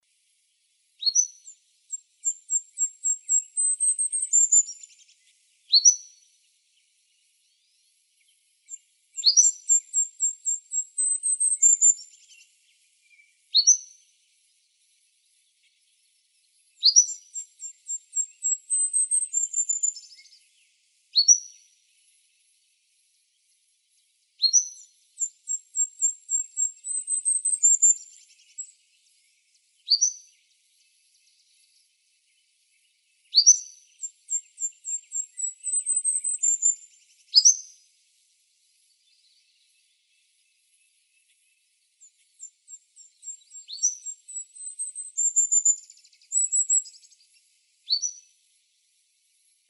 content/hifi-public/sounds/Animals/goldcrowndedkinglet.wav at main
goldcrowndedkinglet.wav